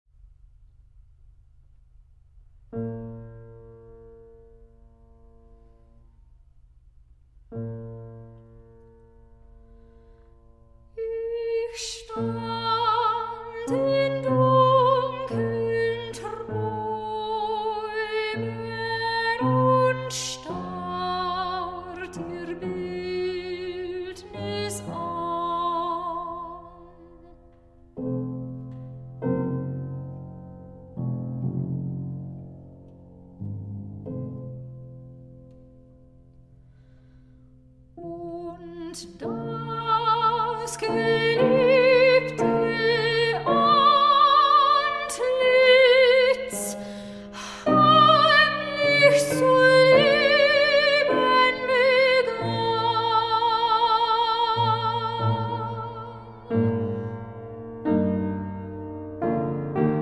soprano
a historically informed performance
fortepiano